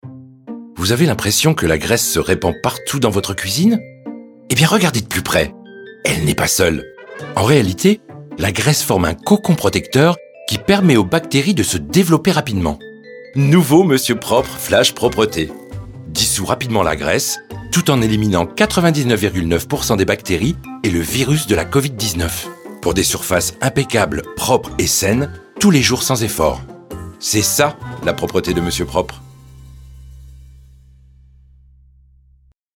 Démo pub produits d'entretien
45 - 65 ans - Basse